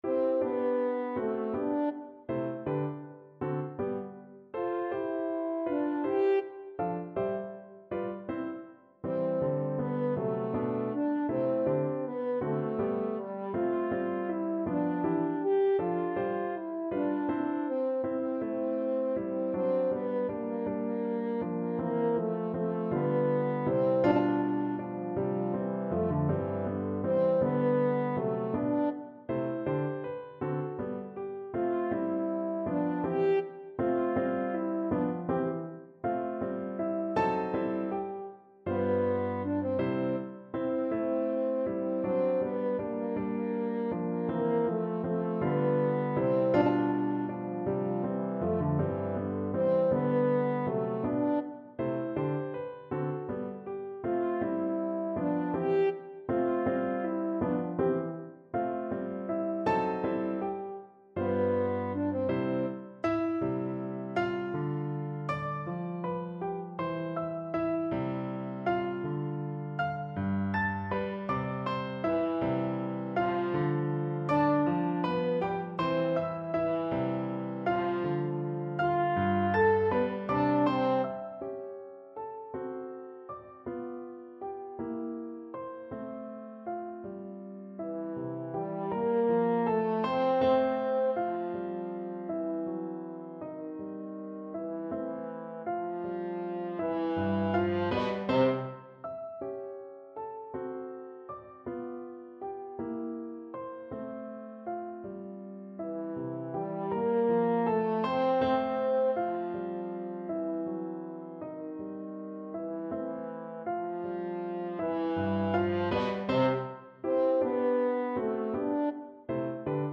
French Horn
3/4 (View more 3/4 Music)
C major (Sounding Pitch) G major (French Horn in F) (View more C major Music for French Horn )
II: Allegretto =160
Classical (View more Classical French Horn Music)